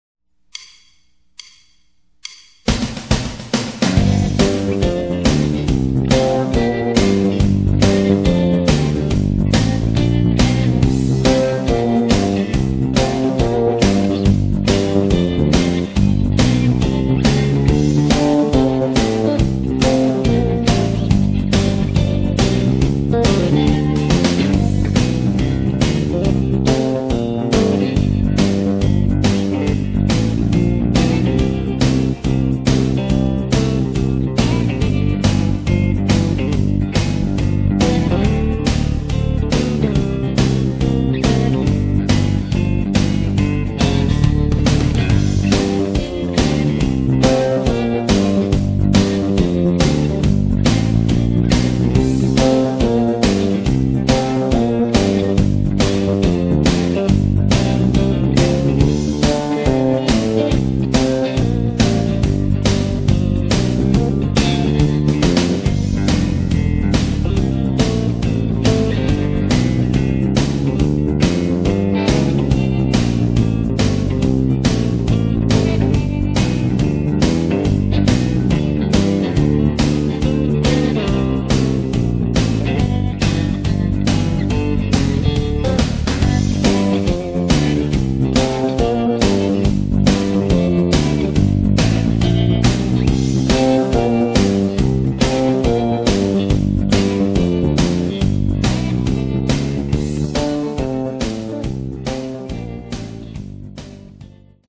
Sitten mennään vauhdikkaasti G-duurista.
Tämä on rootsskaba roots-musiikista kiinnostuneille, joilla ei välttämättä ole taitoa tai kokemusta, jotta julkaisukynnys ylittyisi olemassa oleviin (roots, jazz, soolo, bassolinja) skaboihin.
- taustan tulee olla sointukierroltaan blues (I-IV-V)- sointuja ja tyyliä soveltaen.
ds238_tausta.mp3